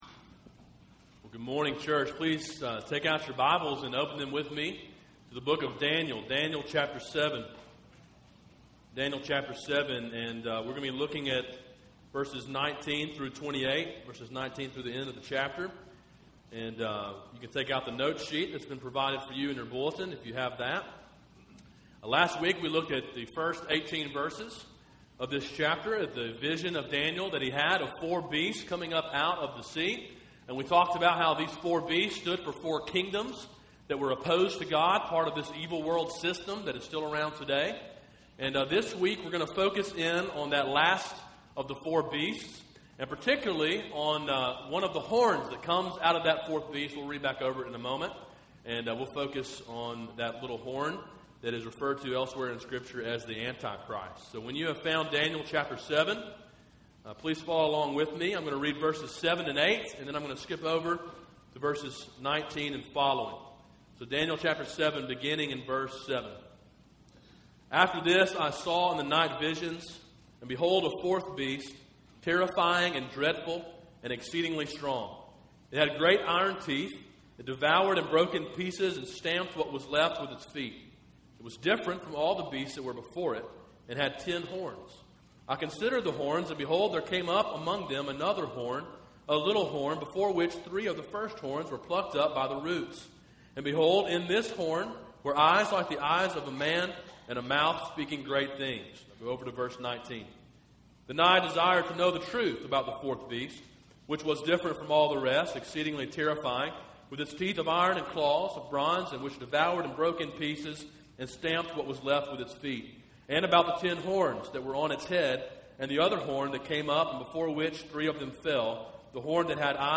A sermon in a series on the book of Daniel.